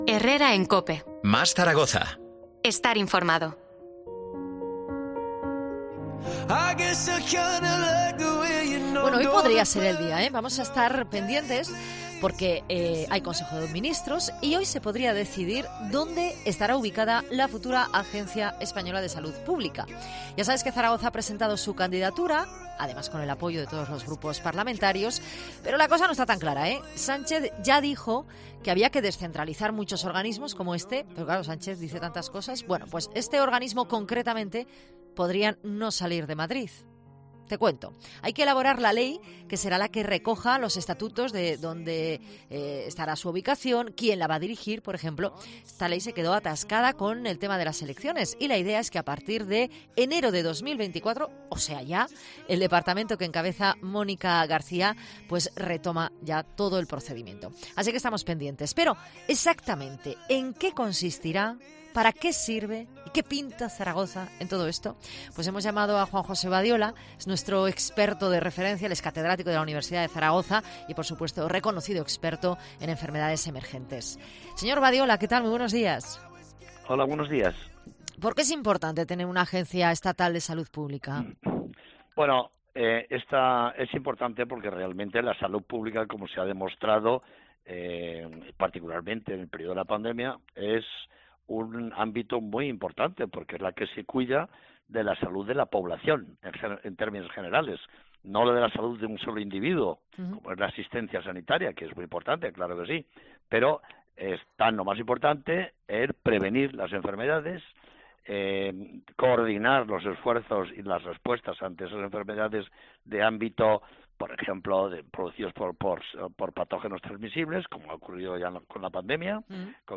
Hablamos con el epidemiólogo